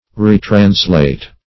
Search Result for " retranslate" : Wordnet 3.0 VERB (1) 1. translate again ; The Collaborative International Dictionary of English v.0.48: Retranslate \Re`trans*late"\, v. t. To translate anew; especially, to translate back into the original language.